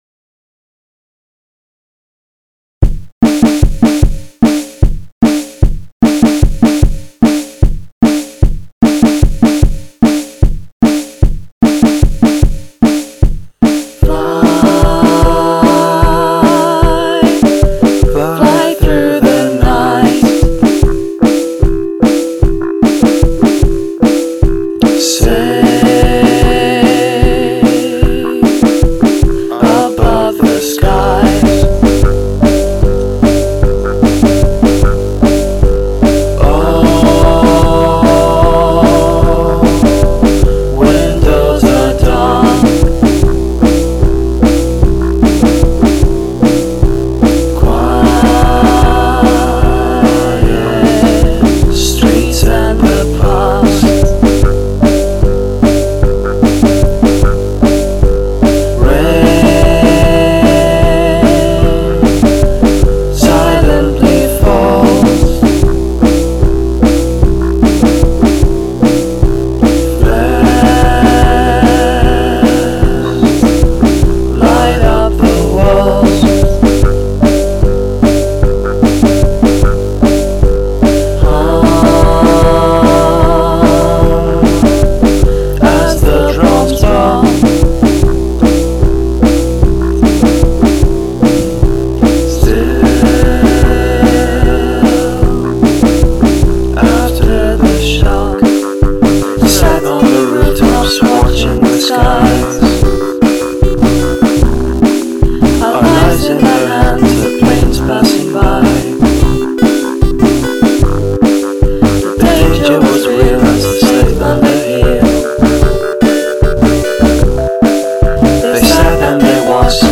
Use of choral voices
The simple beat and music highlight the nice vocals.